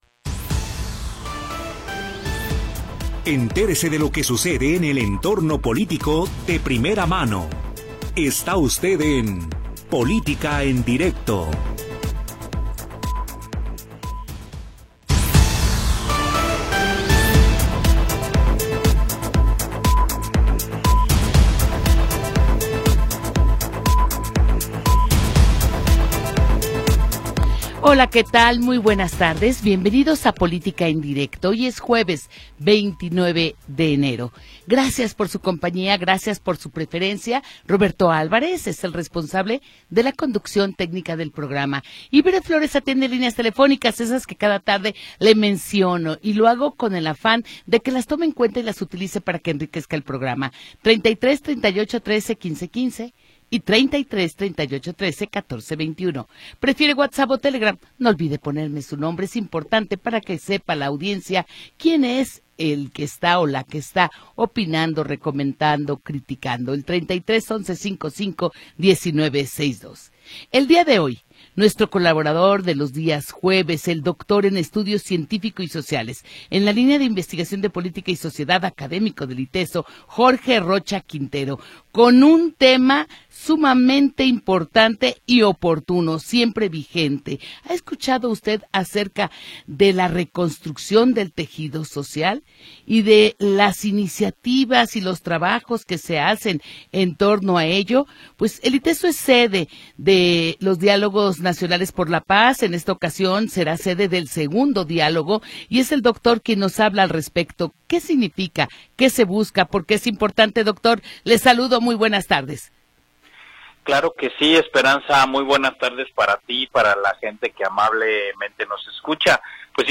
Programa transmitido el 29 de Enero de 2026.